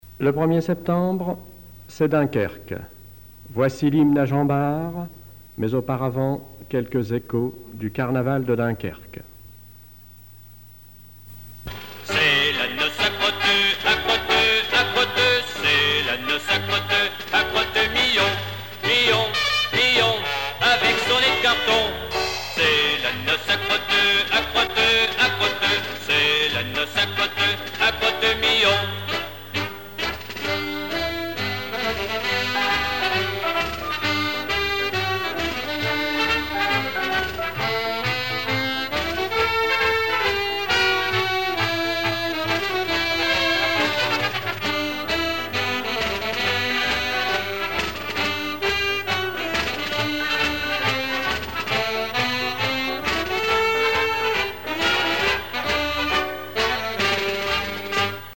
circonstance : carnaval, mardi-gras
Genre strophique
Pièce musicale inédite